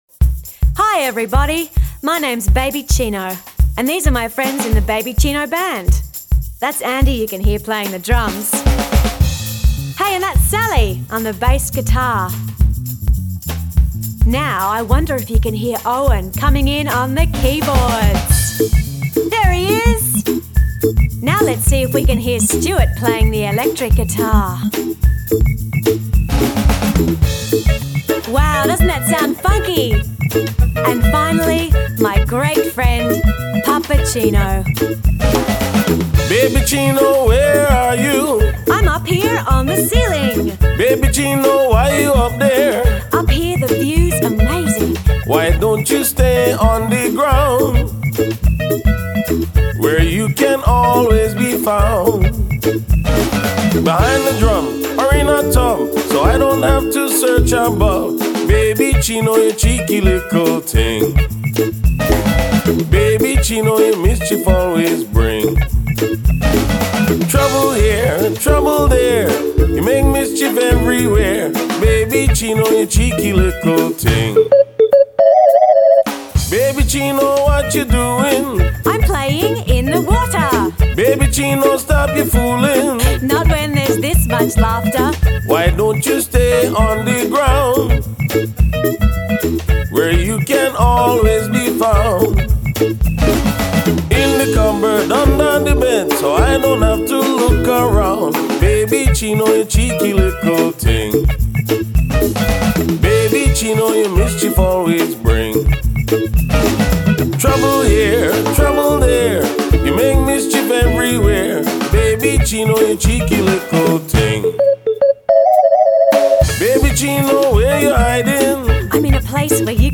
vocals
bass
keyboard
guitar
drums